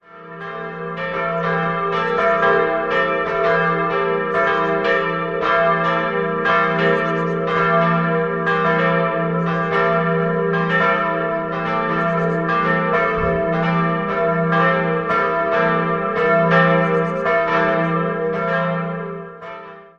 Jahrhundert. 3-stimmiges TeDeum-Geläute: e'-g'-a' Die Glocken wurden 1952 von der Firma Lotter in Bamberg gegossen.